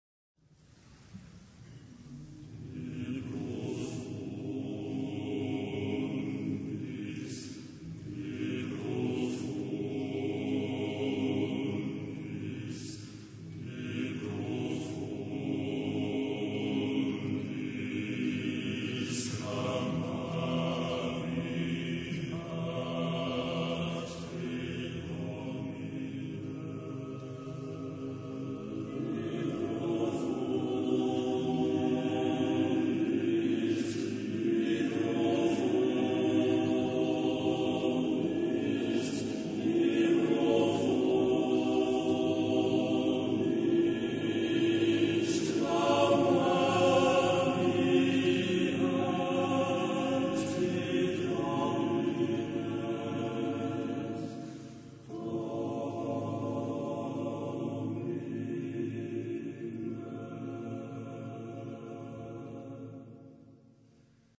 Genre-Stil-Form: geistlich
Chorgattung: TTBB  (12 Männerchor Stimmen )
Solisten: Baryton (1)  (1 Solist(en))